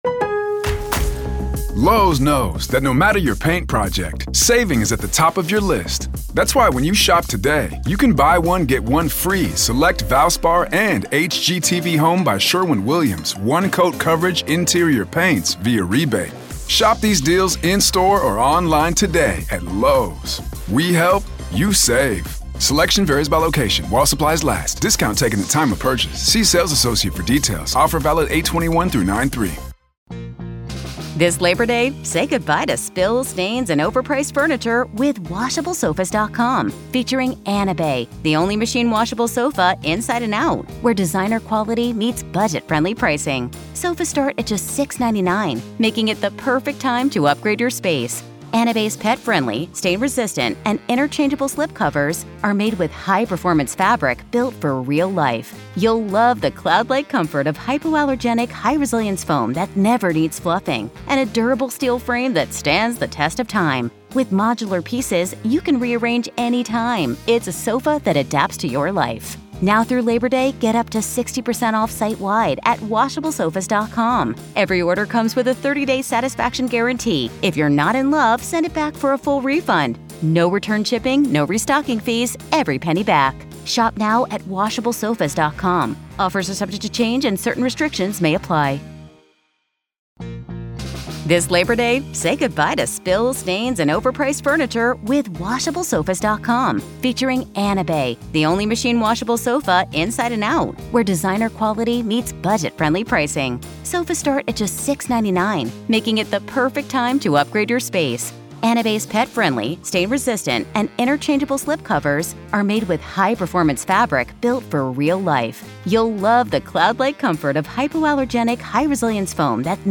Uncover the truth behind the Murdaugh murders: Listen as Alex Murdaugh faces intense questioning from investigators just three days after the tragic slayings of his wife and son. Get an inside look at the crucial early moments of the investigation and the pressure faced by the...